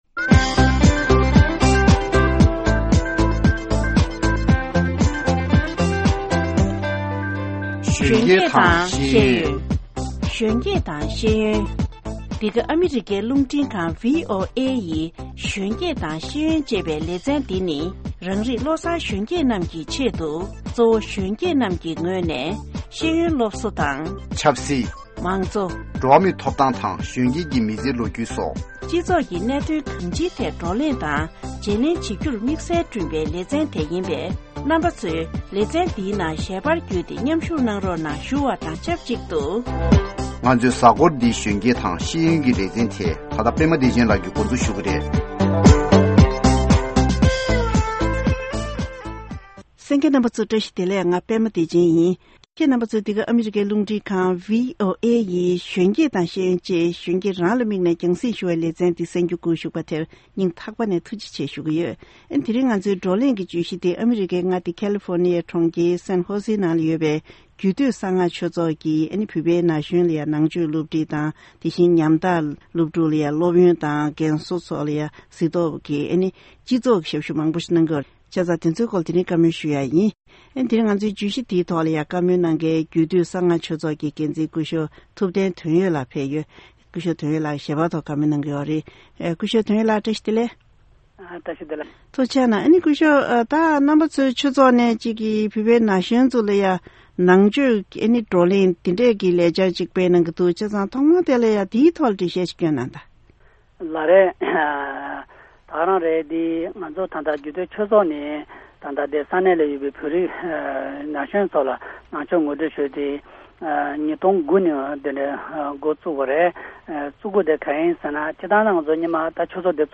གནས་འདྲི་ཞུས་པ་དེ་གསན་གནང་གི་རེད།